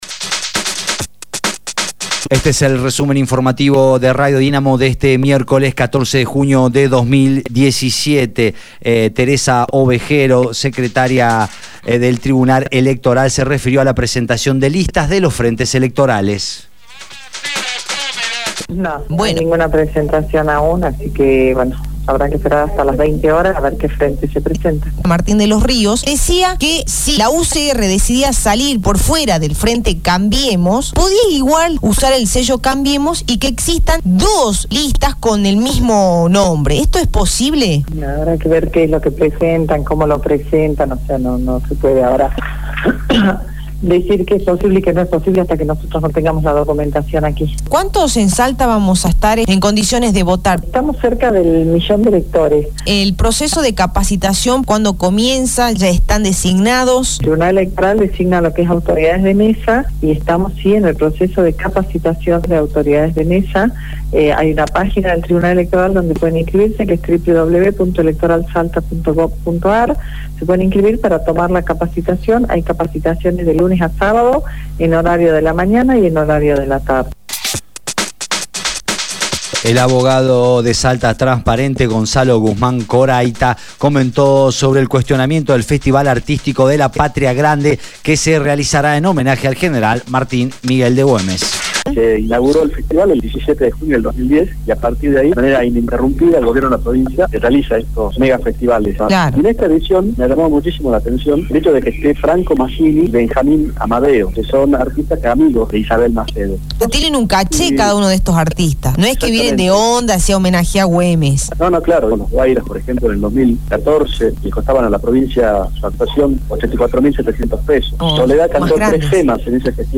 Resumen Informativo de Radio Dinamo del día 15/06/2017 1° Edición